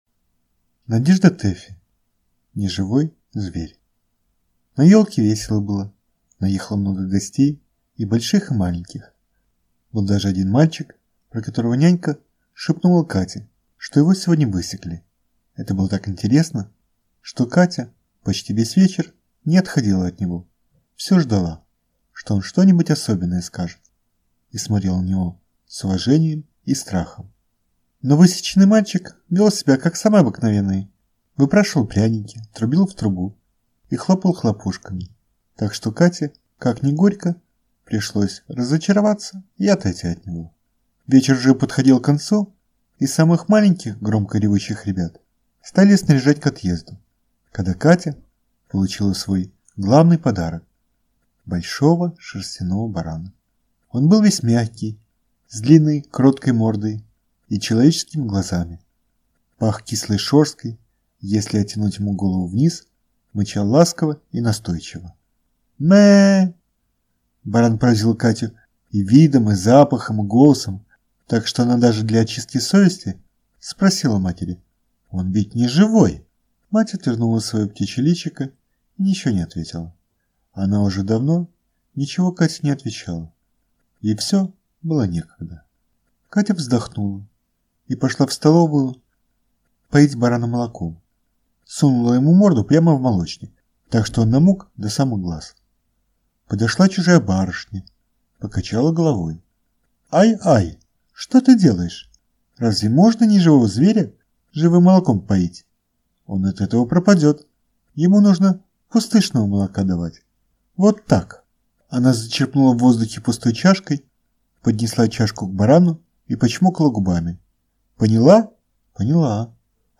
Аудиокнига Неживой зверь | Библиотека аудиокниг